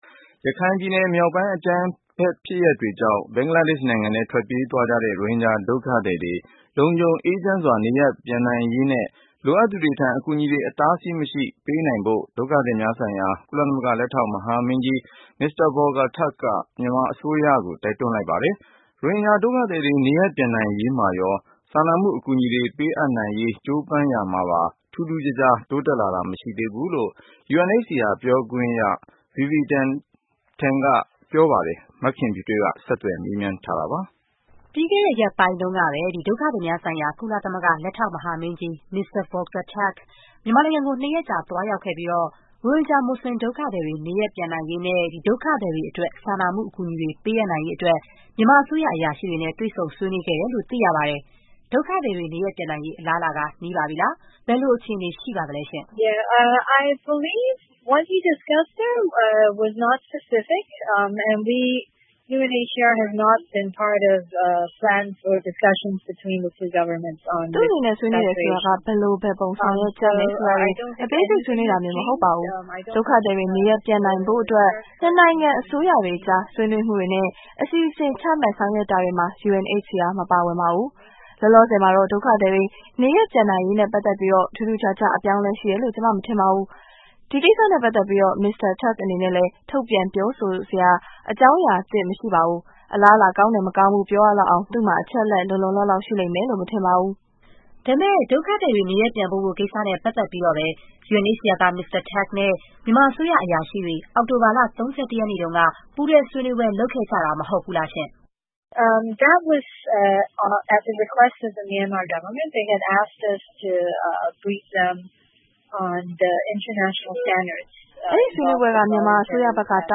ဆက်သွယ်မေးမြန်းထားတာပါ။